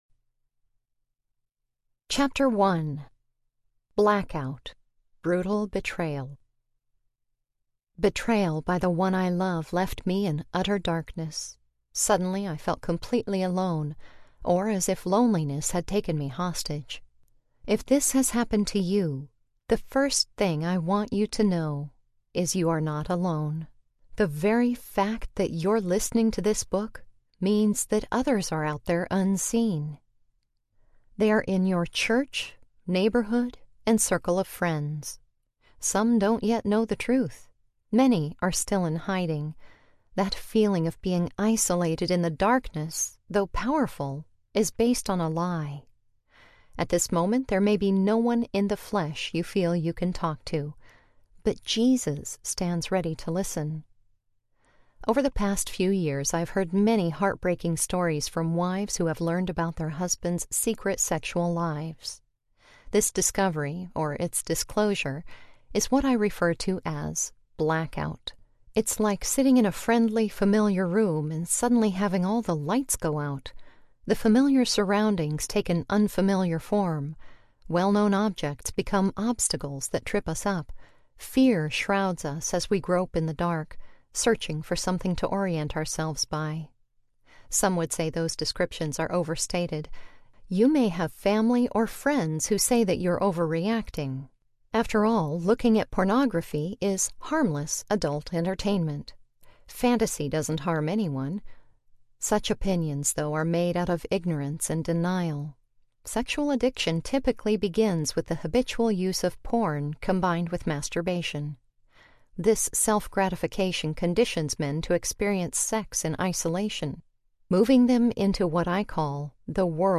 Hope After Betrayal Audiobook
Narrator
6.4 Hrs. – Unabridged